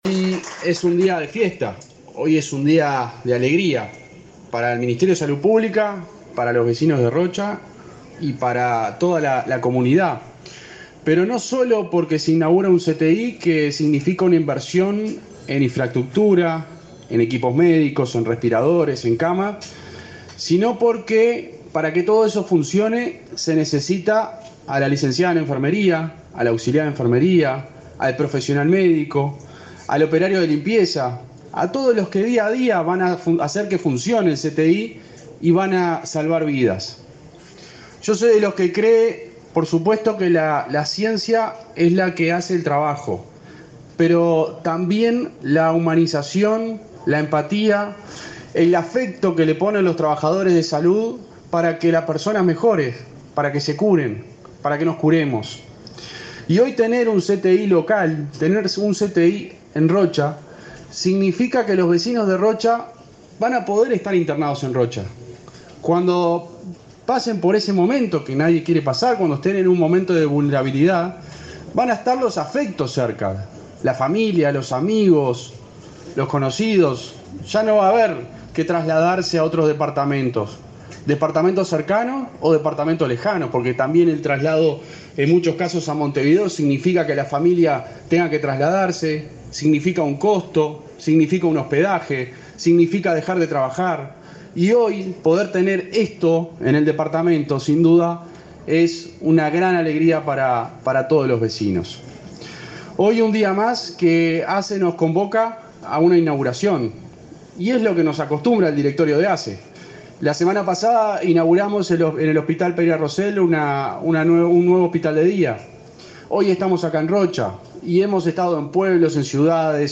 Palabras de autoridades en inauguración de ASSE en Rocha
El subsecretario de Salud Pública, José Luis Satdjian, y el presidente de ASSE, Leonardo Cipriani, participaron en Rocha en la inauguración del centro